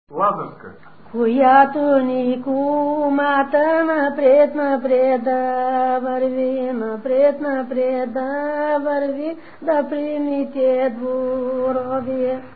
музикална класификация Песен
размер Две четвърти
фактура Едногласна
начин на изпълнение Солово изпълнение на песен
фолклорна област Югоизточна България (Източна Тракия с Подбалкана и Средна гора)
начин на записване Магнетофонна лента